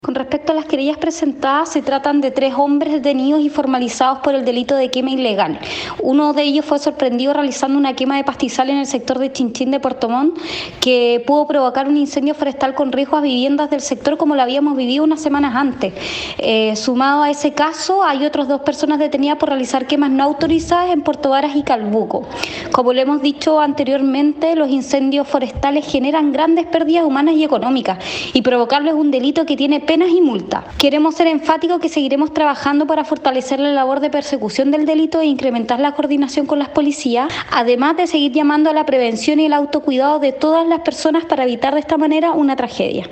La Delegada Presidencial de la región de Los Lagos, Giovanna Moreira, se refirió a la presentación de tres querellas interpuestas por quema ilegal en diferentes puntos de la región: